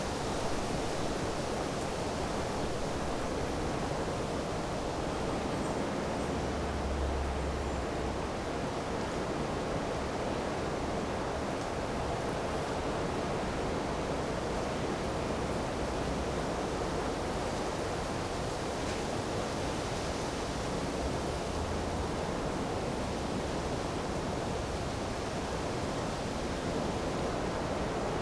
tenkoku_windambient.wav